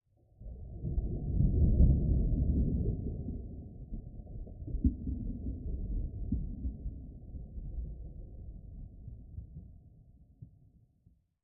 Minecraft Version Minecraft Version latest Latest Release | Latest Snapshot latest / assets / minecraft / sounds / ambient / nether / basalt_deltas / plode3.ogg Compare With Compare With Latest Release | Latest Snapshot